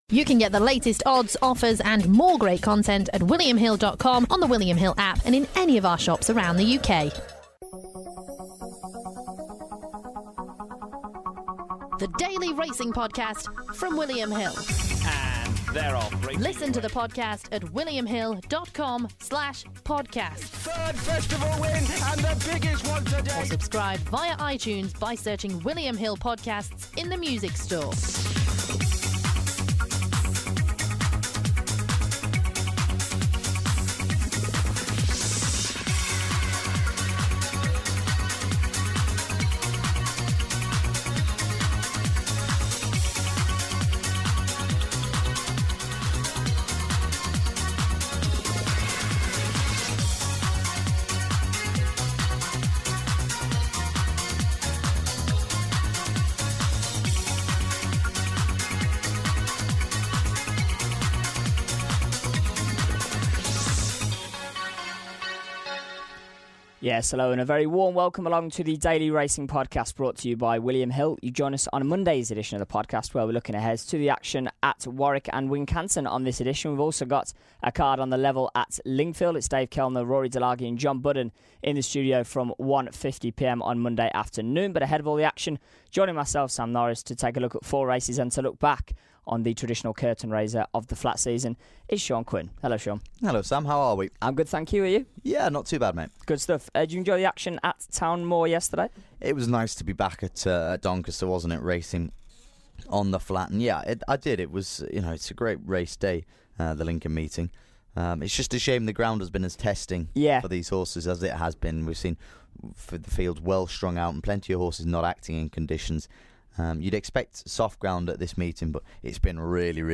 in the studio to reflect on the action from Town Moor on Saturday.